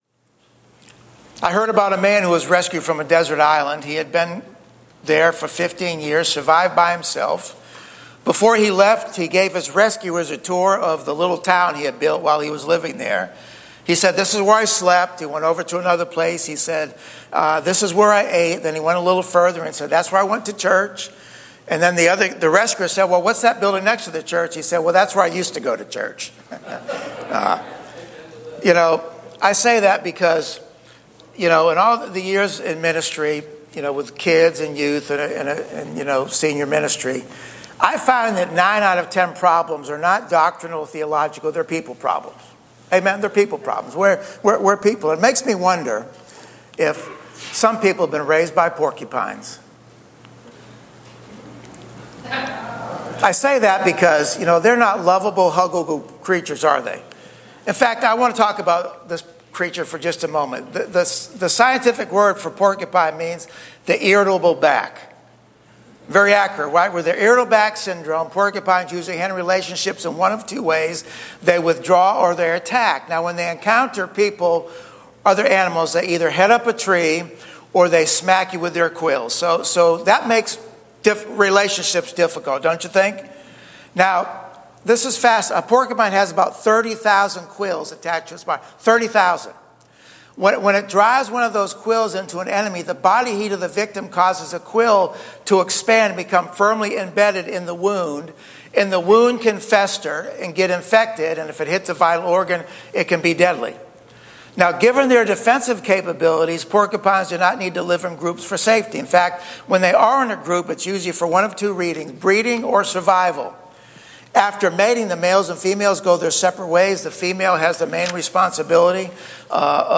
*NOTE: Due to a technical issue, our audio quality for this message was lower than usual.